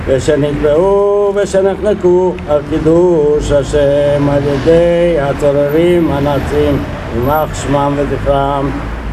”Ziua Holocaustului” a fost marcată astăzi la Târgu Mureș, printr-o ceremonie emoționantă la monumentul de pe strada Călărașilor.
Au participat supraviețuitori ai holocaustului, alături de urmașii lor, care au fost înconjurați de membrii comunității evreiești din Târgu Mureș: